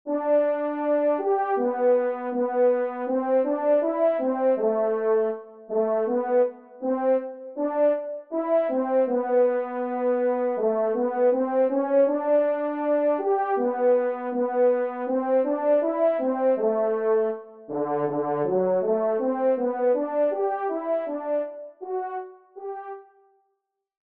Genre : Divertissement pour Trompes ou Cors
Pupitre Trompe ou Cor seul